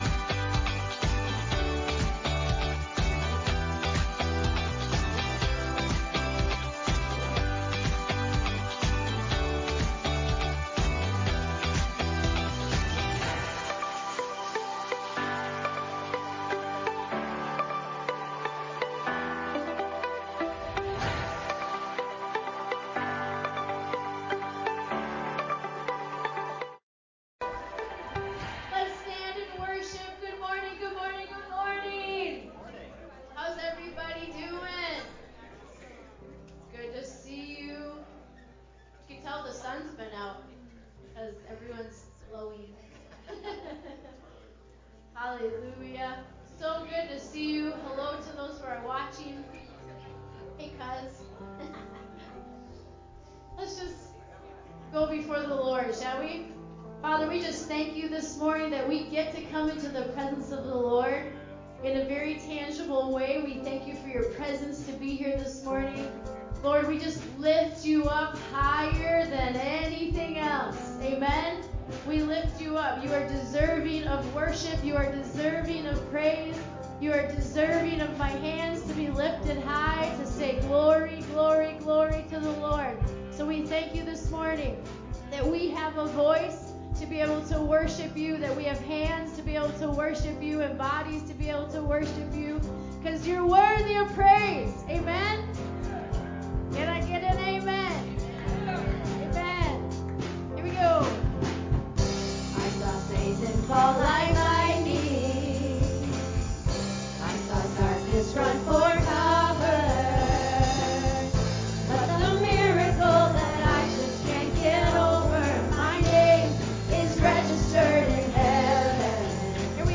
LISTEN (church service)